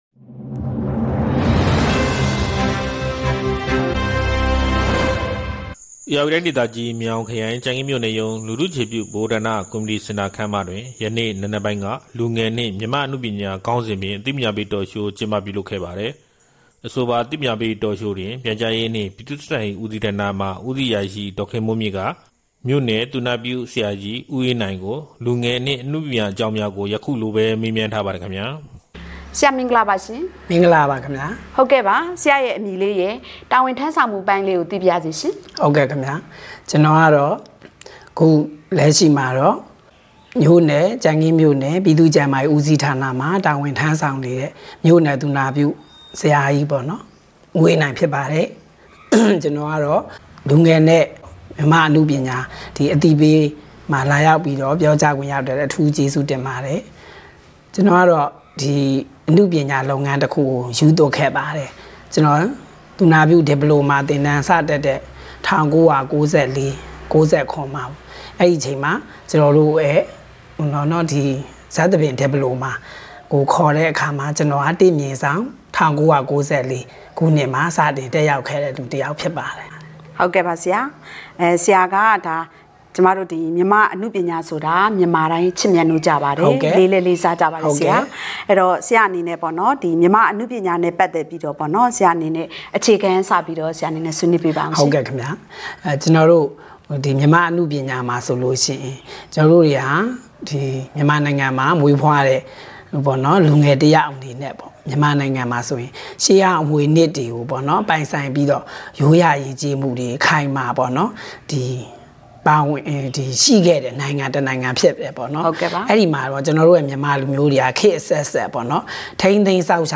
လူထုအခြေပြုဗဟိုဌာနတွင် လူငယ်နှင့် မြန်မာအနုပညာ အသိပညာပေး အပိုင်း(၁) Talk Show ကျင်းပပြုလုပ် ကြံခင်း ဇူလိုင် ၁၃ ပေးပို့သူ